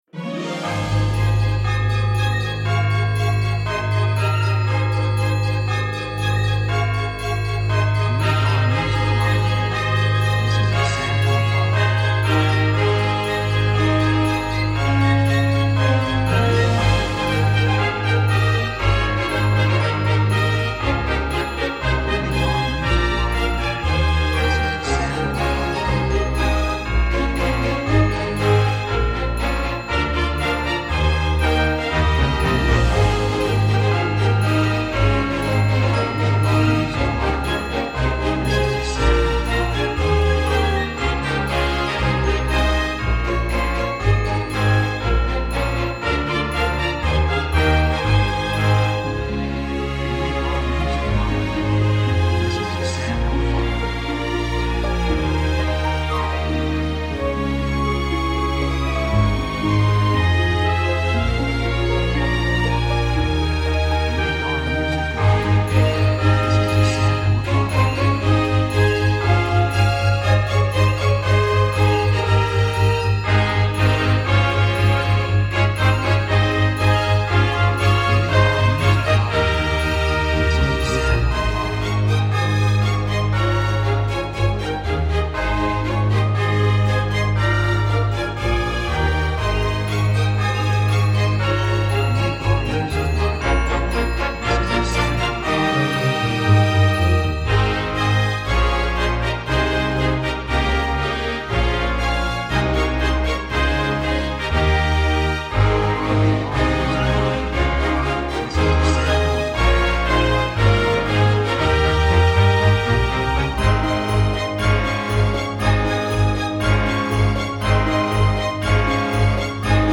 2:57 119 プロモ, 季節物, スコア